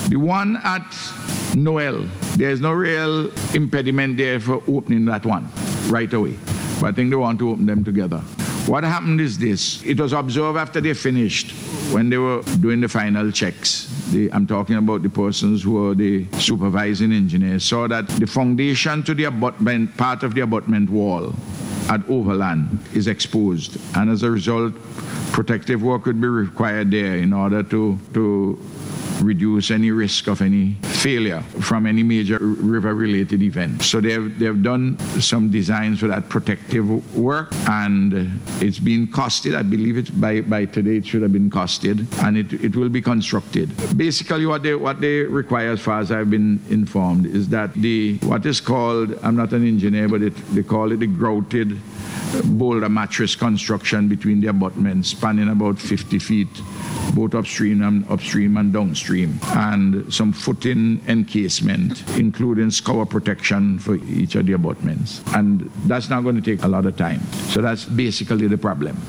That’s according to Prime Minister Hon. Dr. Ralph Gonsalves, who provided the information as he responded to a question in Parliament on Tuesday from Opposition Senator Hon. Shivern John.